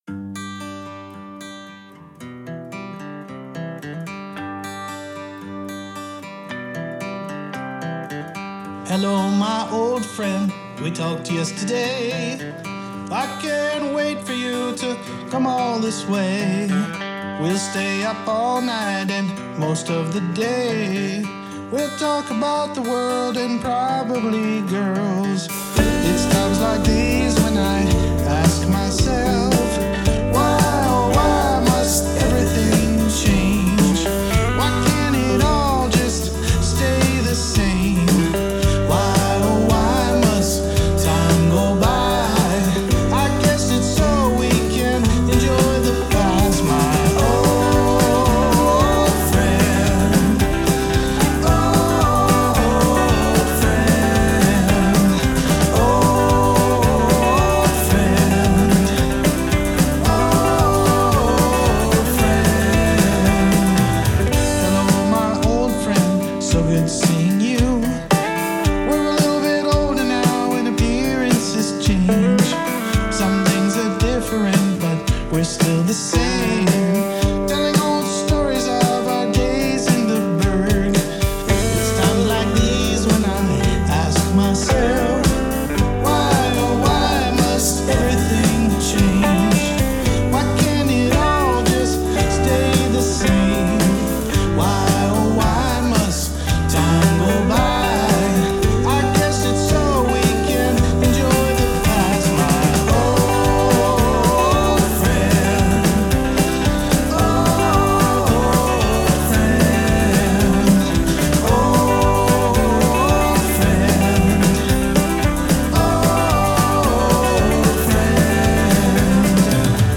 • Genre: Country / Folk